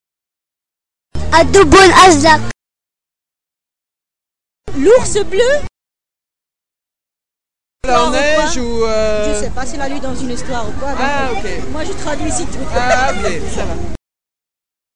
uitspraak abdubulasak voorbeeld l'ours bleu uitleg abdubulasak, mot inventé par enfant